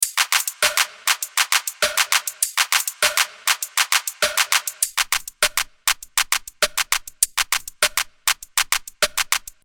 Некие Black Rooster Audio в явно рекламных целях, предваряя обещанные LA2 и LA3, предлагают плагин Canary - процессор для перкуссивных звуков, состоящий из синтезатора, фильтра и транзиент шейпера. Последний и заслуживает внимания, в приведённом примере работает как де-реверб.
Вложения canary_loop_dry_wet.mp3 canary_loop_dry_wet.mp3 381,6 KB · Просмотры: 5.012